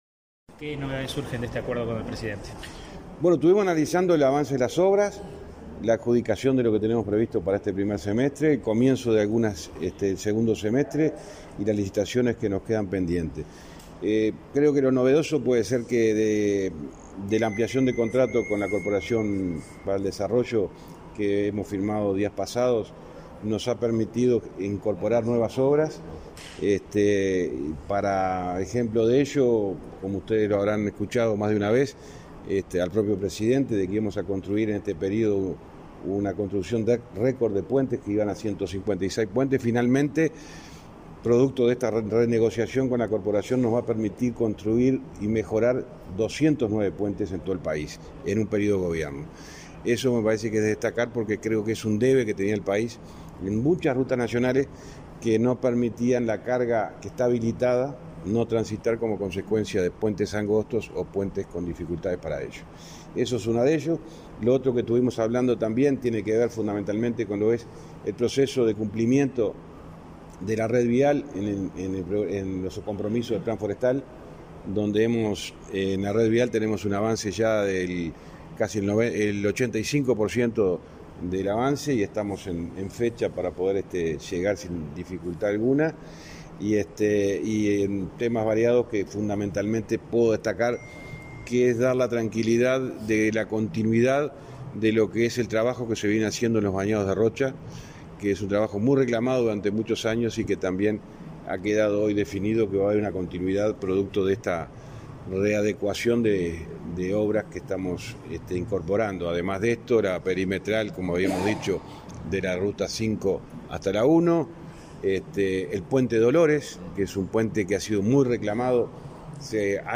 Declaraciones a la prensa del ministro de Transporte y Obras Públicas, José Luis Falero